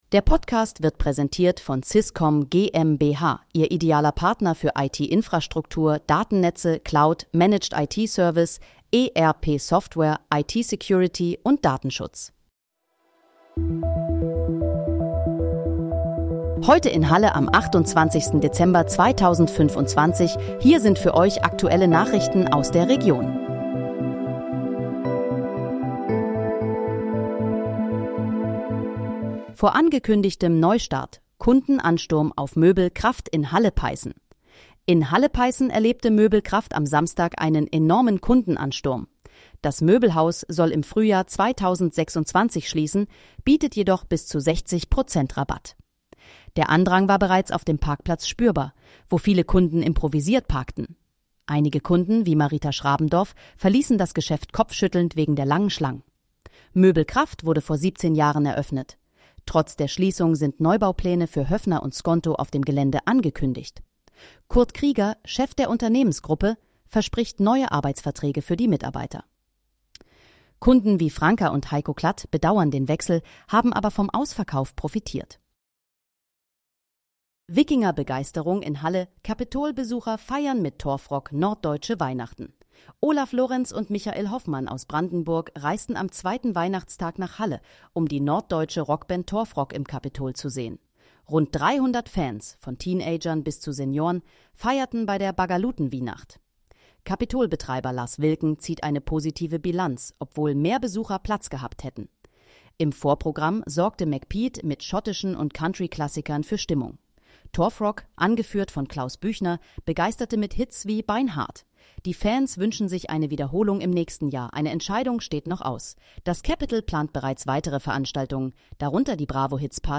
Heute in, Halle: Aktuelle Nachrichten vom 28.12.2025, erstellt mit KI-Unterstützung
Nachrichten